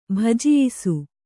♪ bhajiyisu